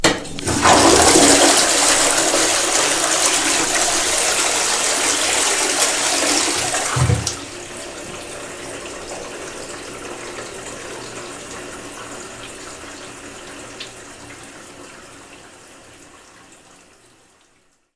厕所
描述：一个相当响亮的厕所正在冲水，这个厕所就在我宿舍旁边。听起来和公共厕所的厕所一样。
标签： 浴室 冲洗 冲洗 响亮 厕所 厕所
声道立体声